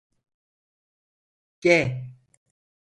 G a 🐌 Significado (Inglés) The eighth letter of the Turkish alphabet, called ge and written in the Latin script. Conceptos G Traducciones G G G G G G G G Frecuencia C1 Pronunciado como (IPA) /ɡ/ Marcar esto como favorito Mejora tu pronunciación Escribe esta palabra Notes Sign in to write sticky notes